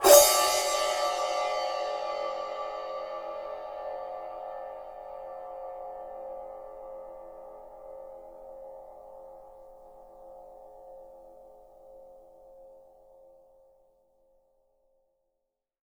• Crash Sound Clip D Key 02.wav
Royality free crash cymbal drum sample tuned to the D note.
crash-sound-clip-d-key-02-xZC.wav